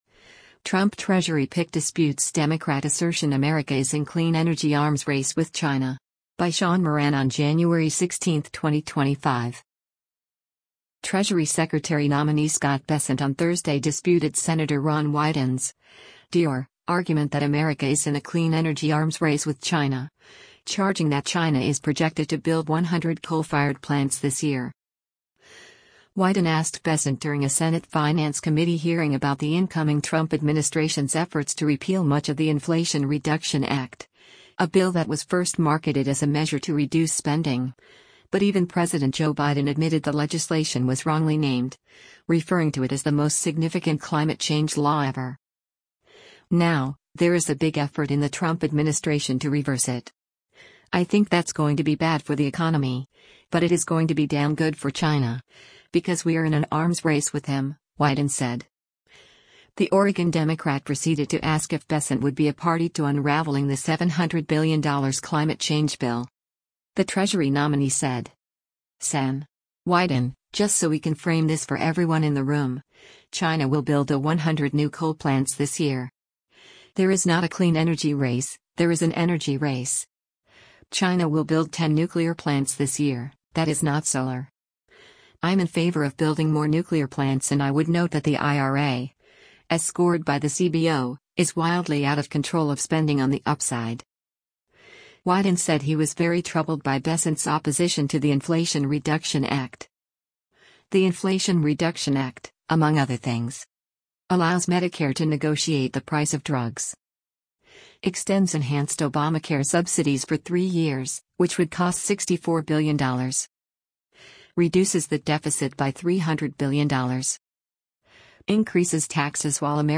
Billionaire hedge fund manager Scott Bessent testifies before the Senate Finance Committee